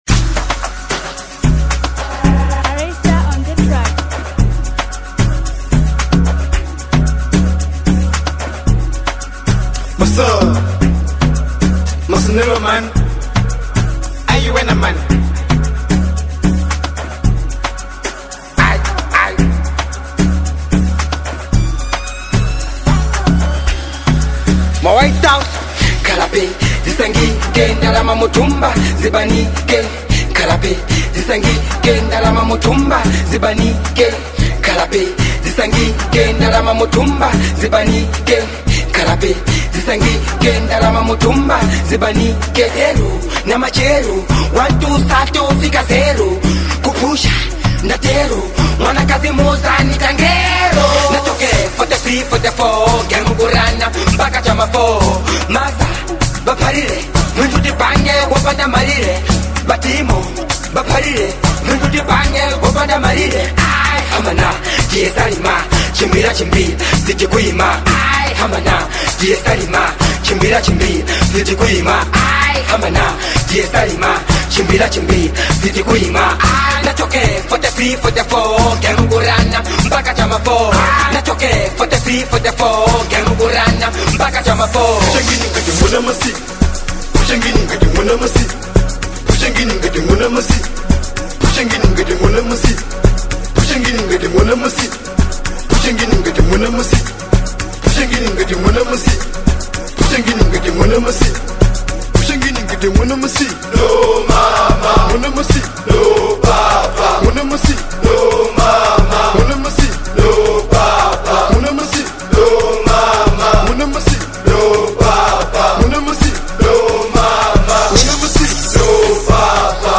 Genre : Amapiano
a fresh Amapiano banger